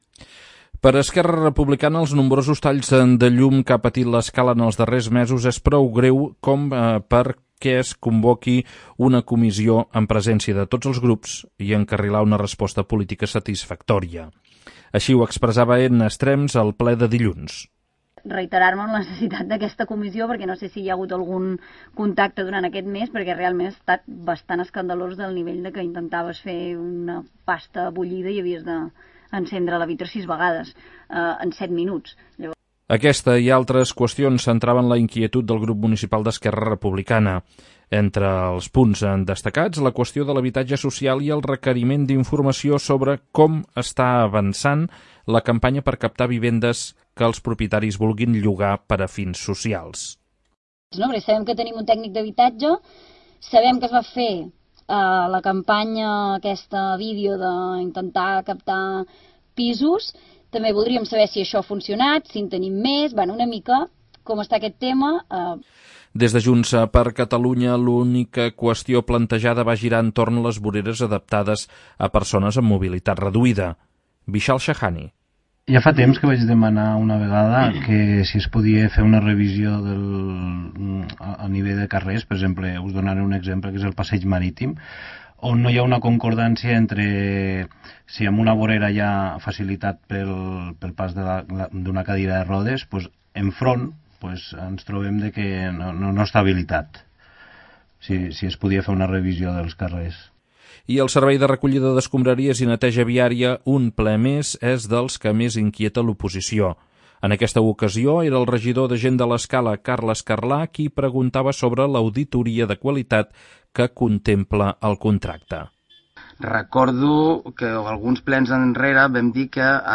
Els problemes amb la neteja, amb els talls de llum, amb les voreres no adaptades o l'habitatge social, centren les inquietuds de l'oposició, expresades a l'apartat de precs i preguntes del ple municipal. El govern es compromet a convocar les comissions d'urbanisme, de cultura així com els consells municipals de salut i de seguretat per tractar totes aquestes qüestions.
Marta Rodeja va intervenir per detallar que les queixes sobre el servei de llum es podran canalitzar a través de la comissió d'urbanisme que es convocarà, com la de cultura, aquest mateix mes d'octubre.